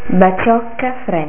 baciòcca frenta